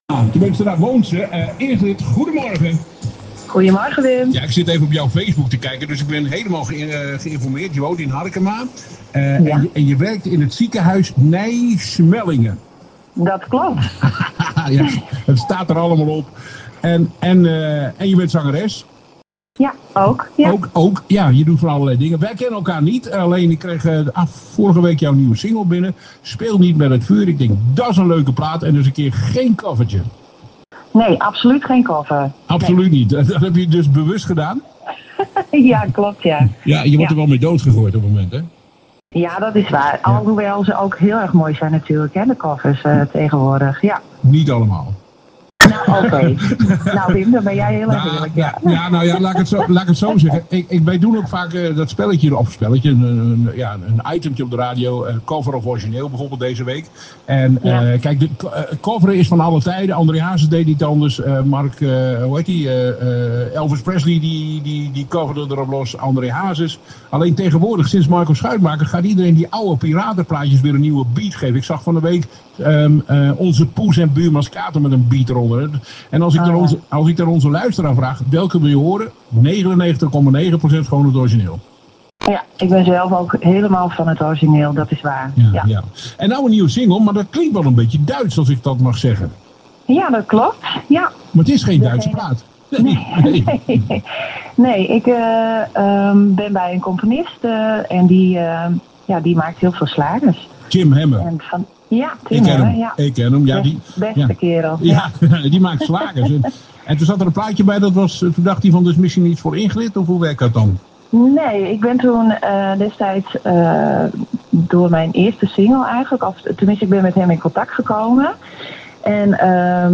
Interview Hitzzz da's radio